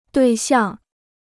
对象 (duì xiàng): target; object; partner; boyfriend; girlfriend.